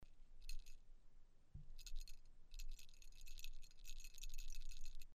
Sound recordings of original pellet bells from the cemeteries Radvaň nad Dunajom-Žitava I (Žitavská Tôň), Holiare and Skalika, SK.
Original sound of Avar pellet bells from the cemeteries
Sound recording of original pellet bell_2137_Radvaň_nad_Dunajom_Žitava_I_grave_10 0.08 MB